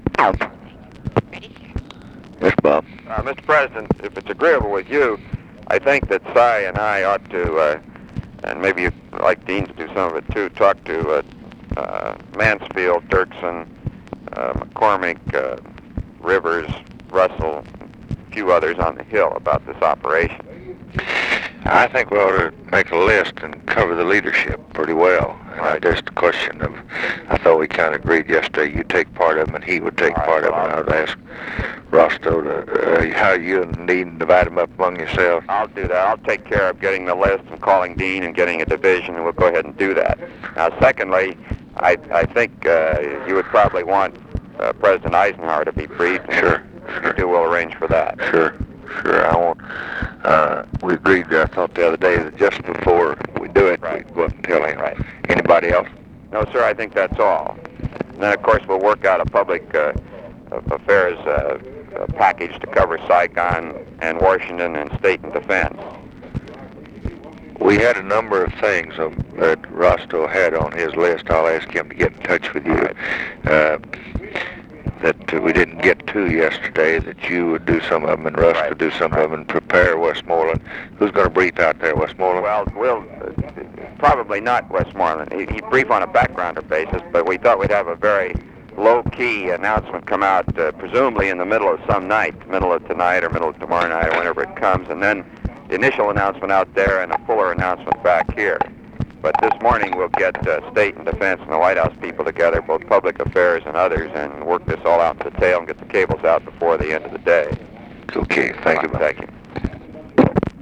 Conversation with ROBERT MCNAMARA, June 23, 1966
Secret White House Tapes